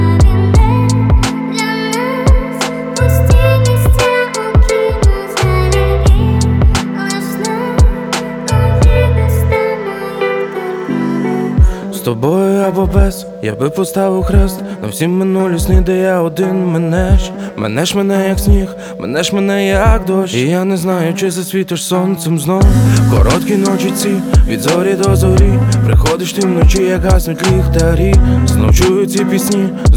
Жанр: Поп / Украинские